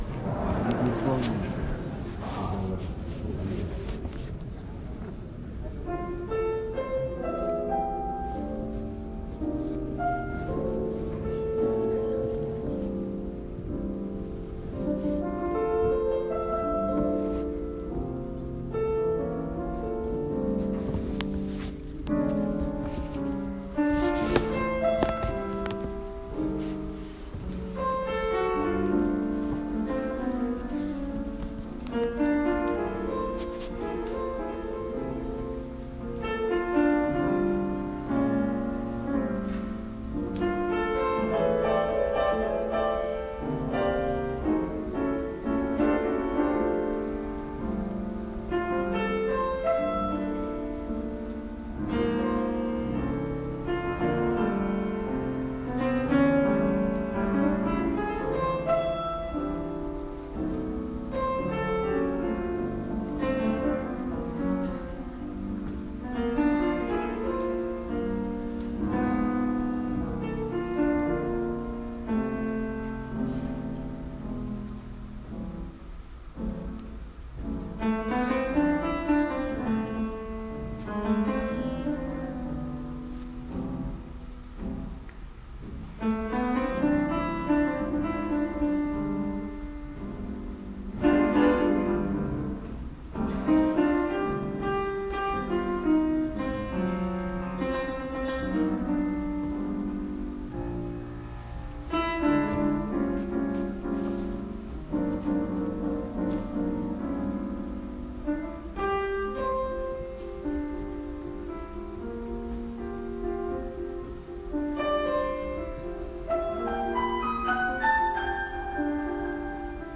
el pianista que iba a estar es un jazzista francés muy bacán
súper mega standards de jazz